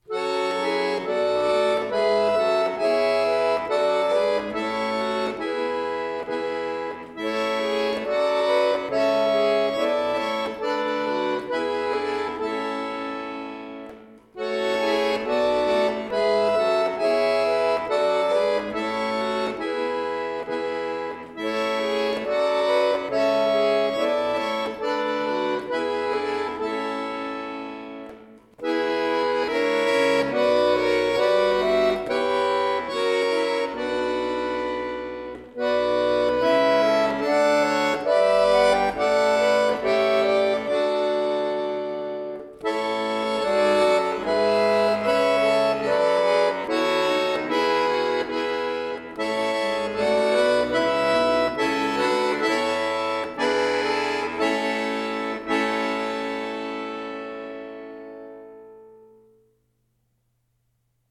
Klassisch